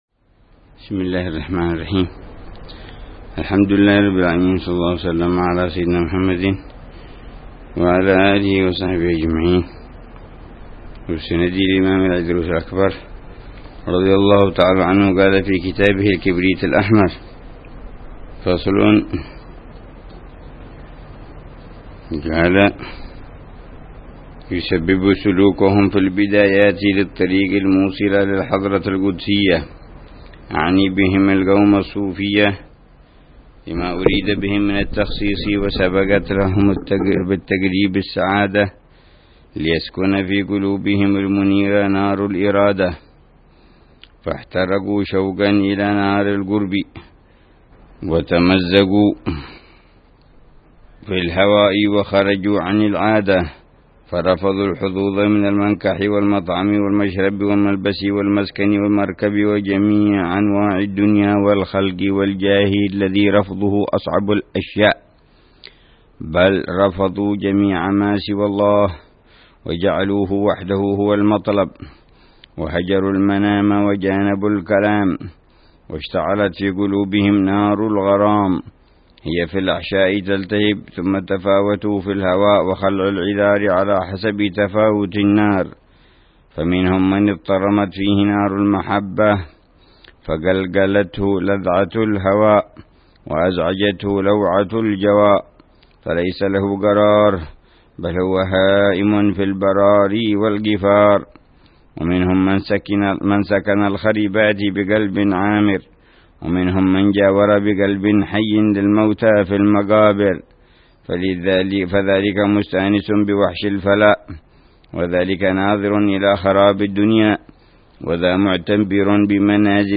درس أسبوعي يلقيه الحبيب عمر بن حفيظ في كتاب الكبريت الأحمر للإمام عبد الله بن أبي بكر العيدروس يتحدث عن مسائل مهمة في تزكية النفس وإصلاح القلب